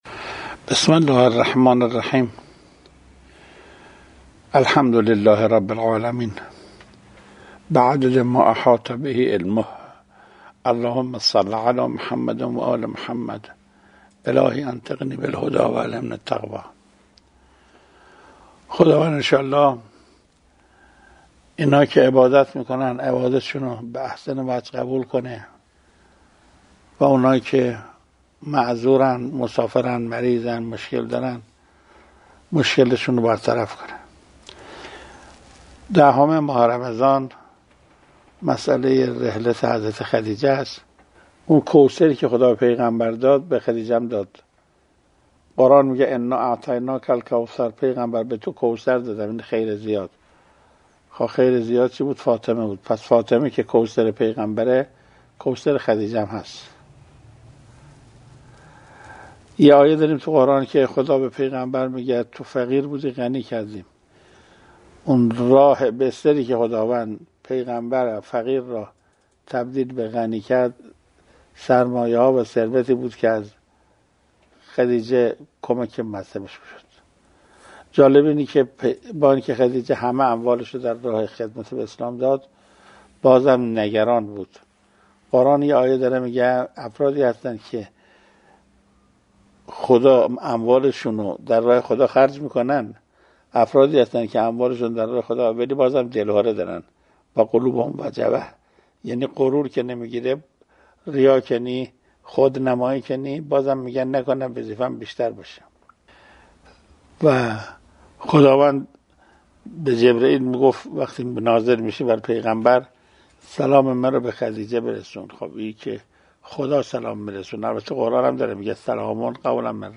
گفتاری از حجت‌الاسلام قرائتی در بیان یک ویژگی قرآنیِ حضرت خدیجه (س) - تسنیم